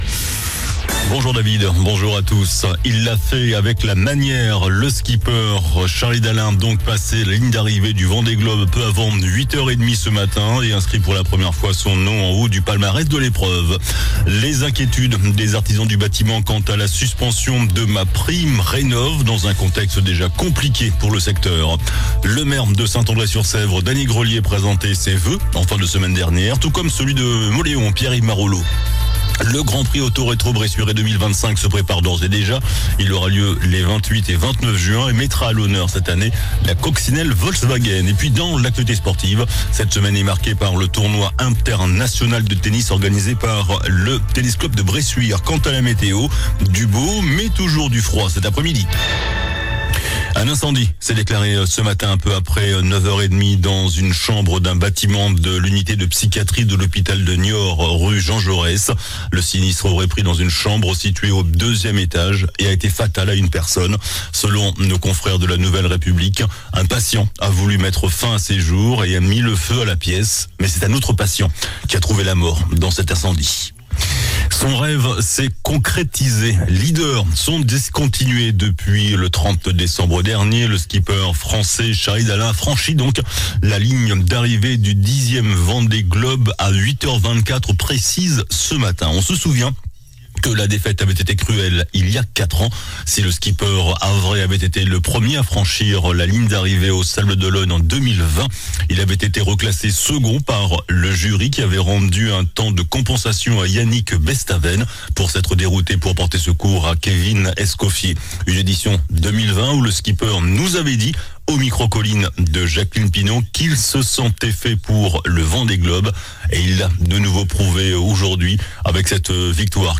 JOURNAL DU MARDI 14 JANVIER ( MIDI )